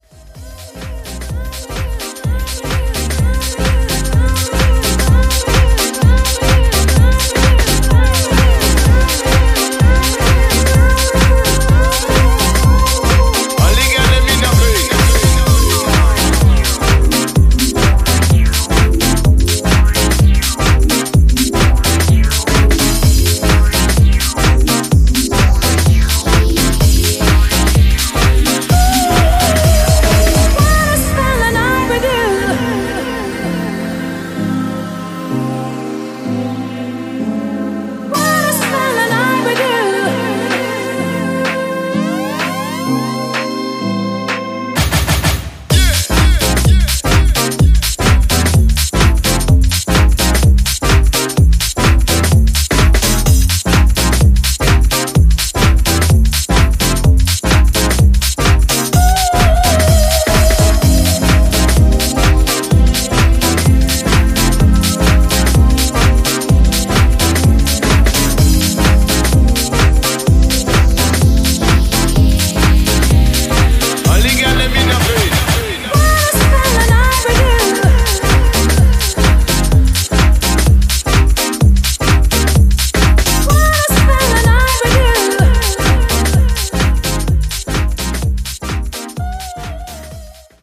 ジャンル(スタイル) HOUSE CLASSIC / UK GARAGE / DEEP HOUSE